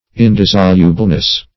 Indissolubleness \In*dis"so*lu*ble*ness\, n.
indissolubleness.mp3